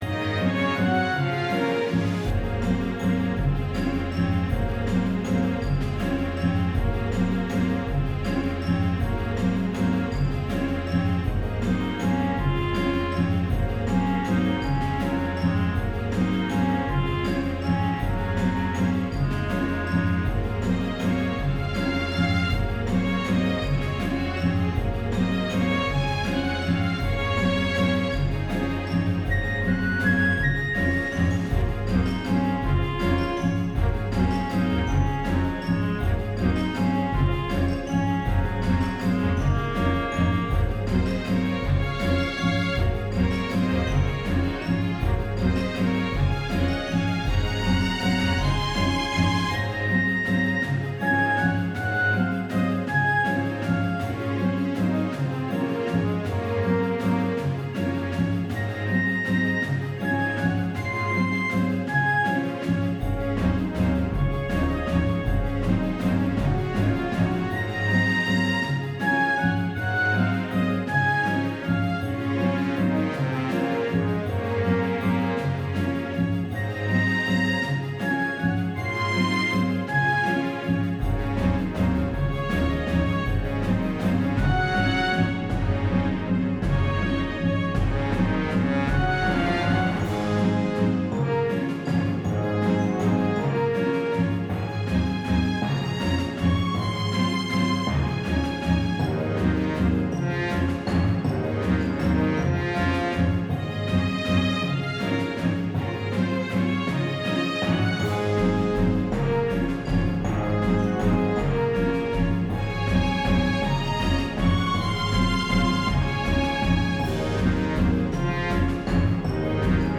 Orchestral dungeon music
orchestral